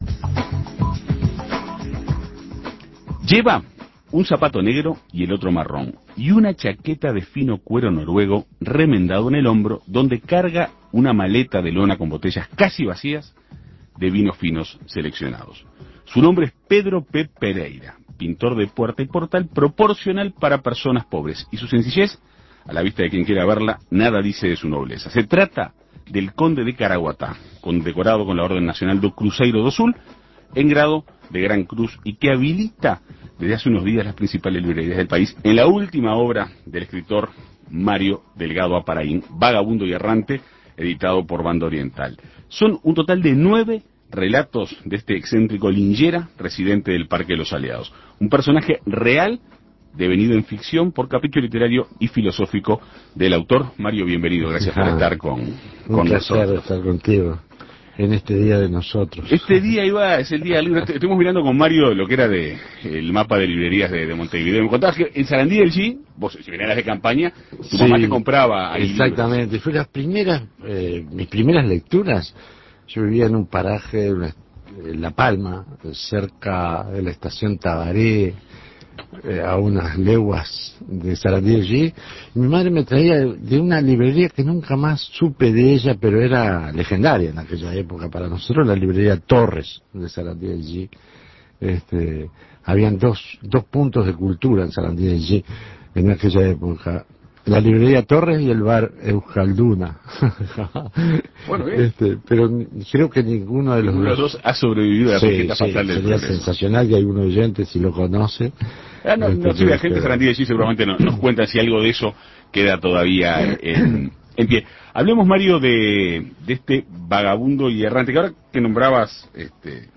Se trata del conde de Caraguatá, condecorado con la Orden Nacional do Cruzeiro do Sul en grado de Gran Cruz y que habita desde hace unos días las principales librerías del país en la última obra del escritor Mario Delgado Aparaín, "Vagabundo y Errante", editado por Banda Oriental. En Perspectiva Segunda Mañana dialogó con el autor para introducirse en los detalles de esta particular obra basada en un personaje real.